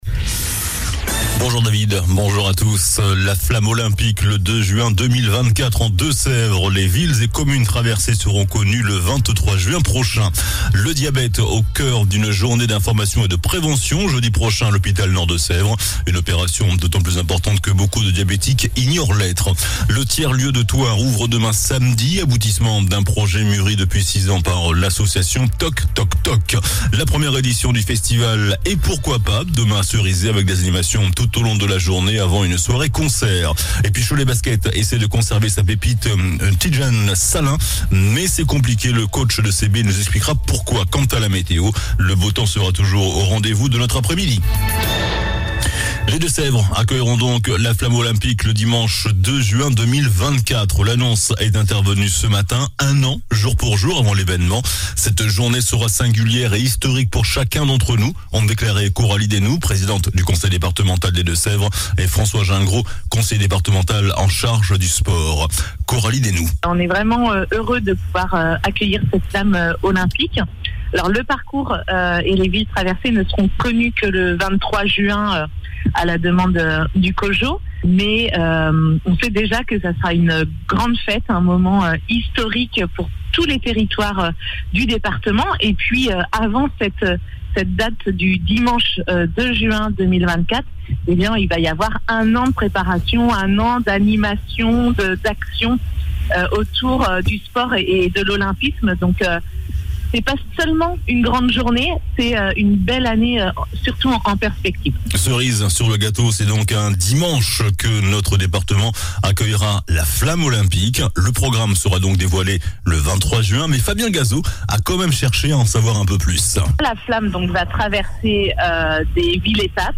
JOURNAL DU VENDREDI 02 JUIN ( MIDI )